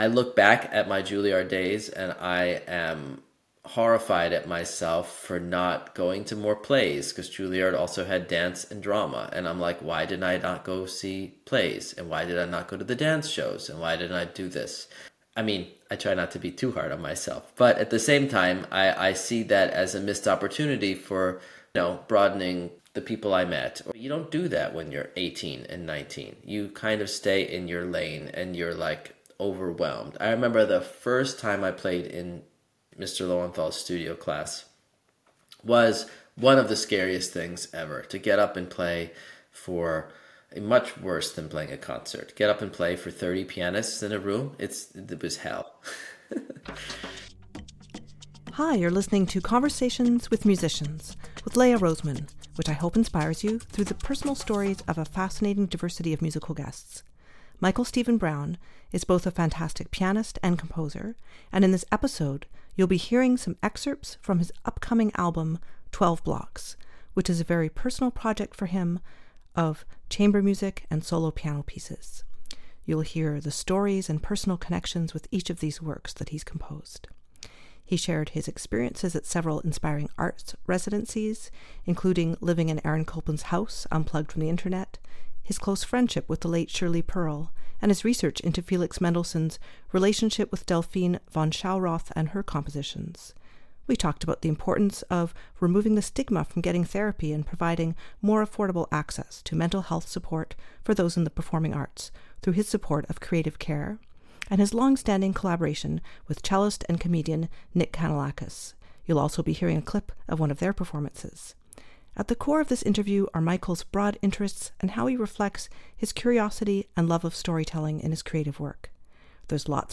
There’s a fascinating variety to a life in music; this series features wonderful musicians worldwide with in-depth conversations and great music. Many episodes feature guests playing music spontaneously as part of the episode or sharing performances and albums.